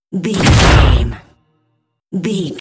Dramatic hit laser shot
Sound Effects
heavy
intense
dark
aggressive